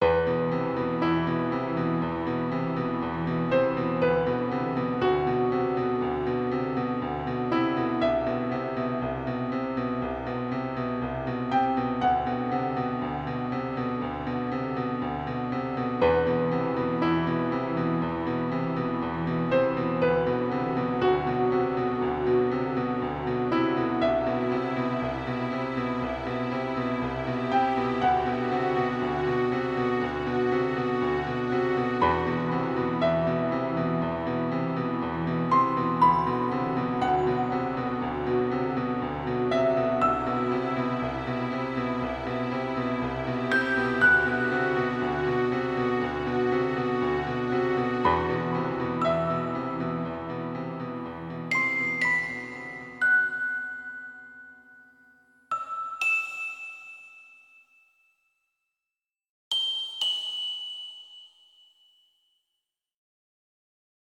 Category 🎬 Movies & TV